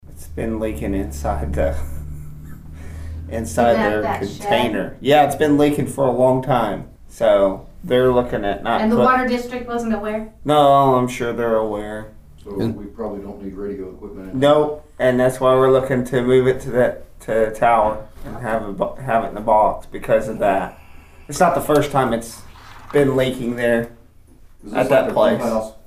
Commissioner Brandon Wesson talked about a leak at a tower in Wann.